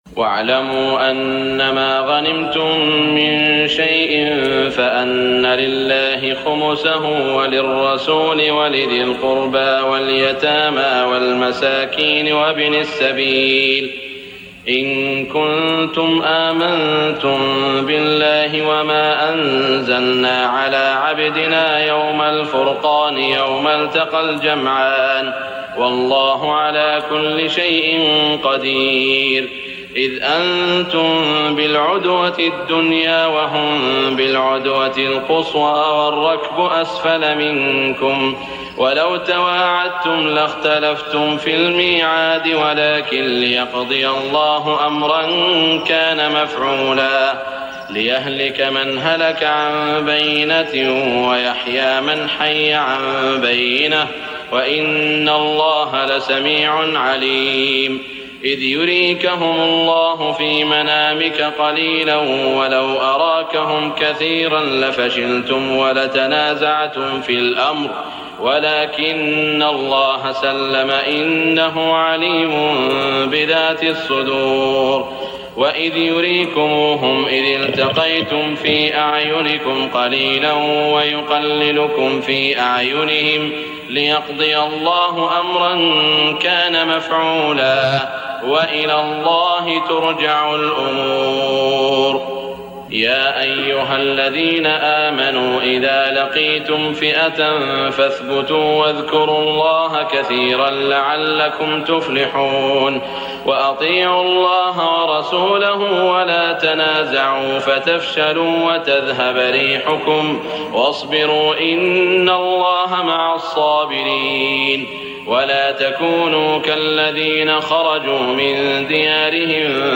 تراويح ليلة 30 رمضان 1418هـ من سورتي الأنفال (41-75) و التوبة (1-33) Taraweeh 30 st night Ramadan 1418H from Surah Al-Anfal and At-Tawba > تراويح الحرم المكي عام 1418 🕋 > التراويح - تلاوات الحرمين